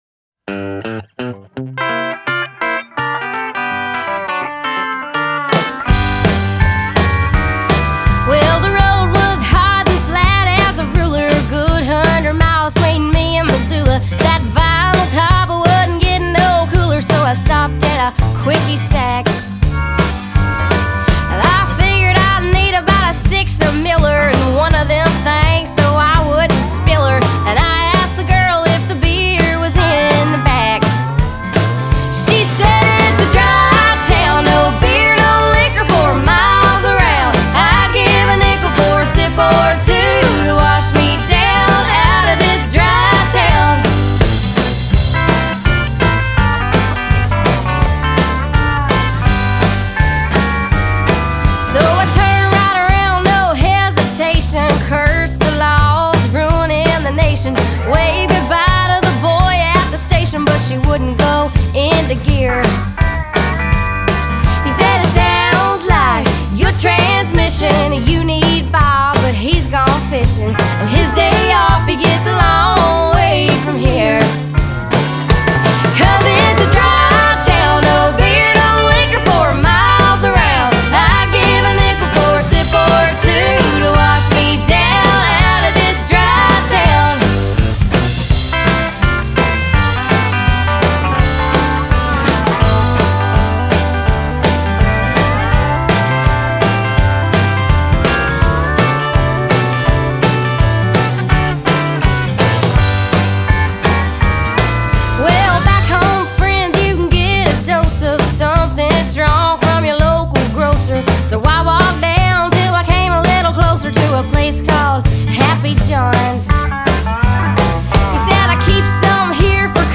But today I’m offering up one of the record’s three covers.